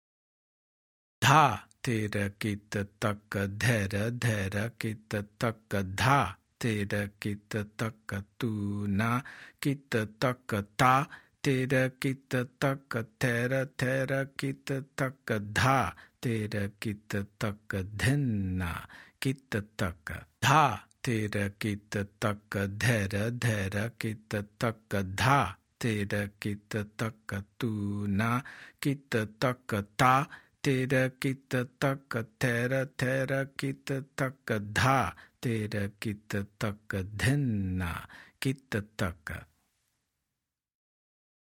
Spoken – Slow